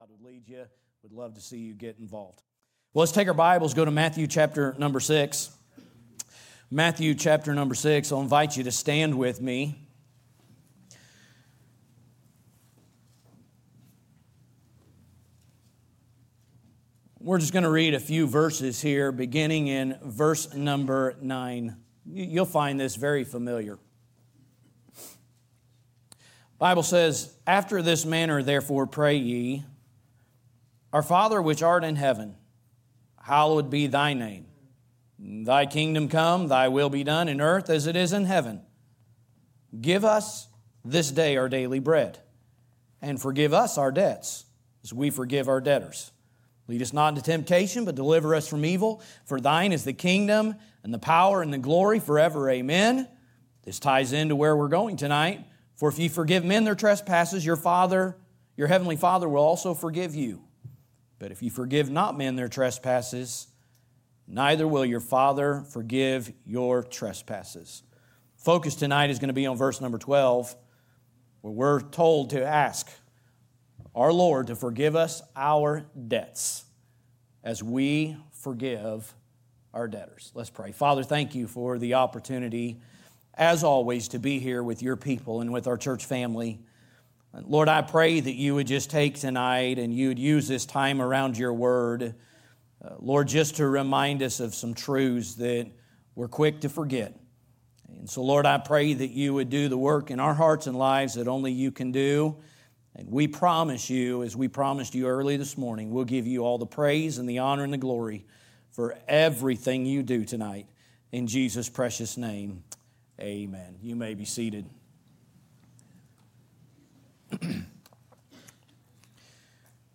Assorted messages